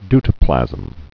(dtə-plăzəm, dy-)